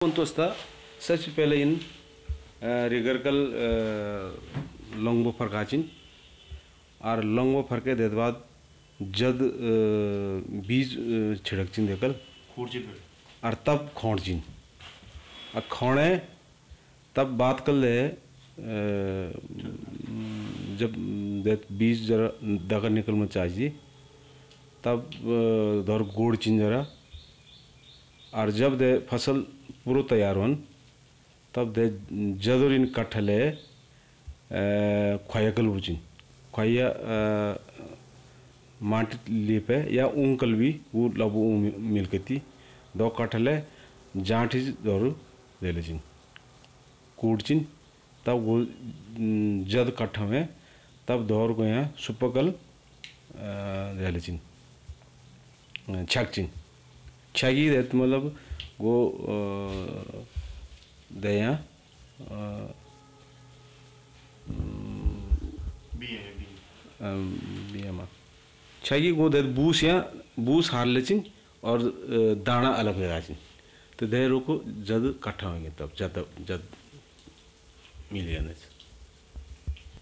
Retelling of a song in Rongpo